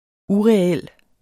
Udtale [ ˈuʁεˌεlˀ ]